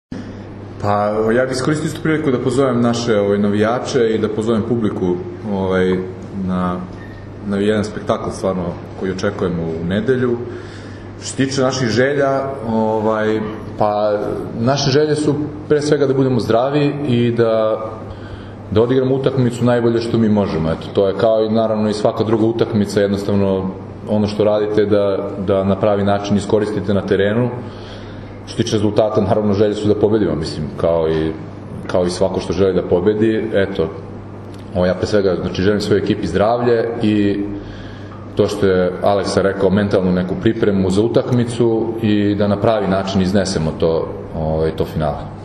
U beogradskom hotelu “M” danas je održana konferencija za novinare povodom finalnih utakmica 59. Kupa Srbije 2024/2025. u konkurenciji odbojkašica i jubilarnog, 60. Kupa Srbije 2024/2025. u konkurenciji odbojkaša, koje će se odigrati se u “MTS hali Jezero” u Kragujevcu u nedelju, 23. februara.
Izjava